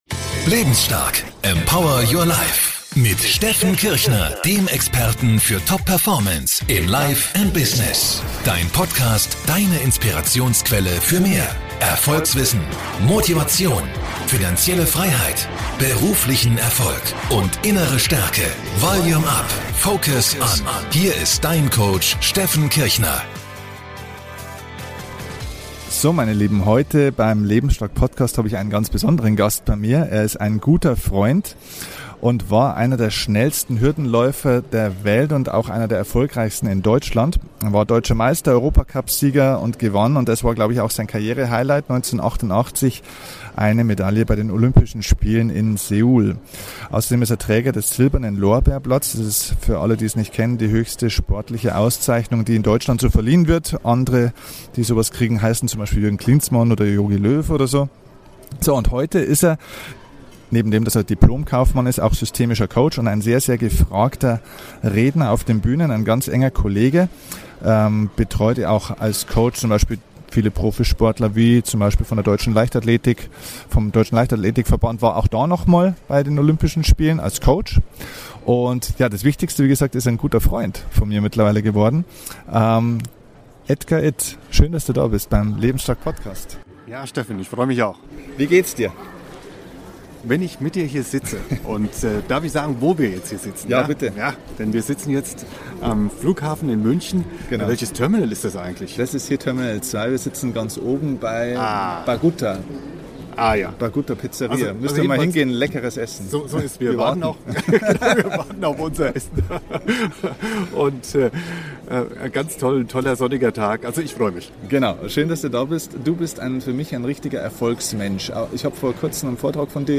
Beschreibung vor 8 Jahren # 035 Über Hürden zum Erfolg – Interview mit Olympiamedaillen-Gewinner Edgar Itt Erfolg bedeutet, Rückschläge im Leben in etwas Positives umwandeln zu können.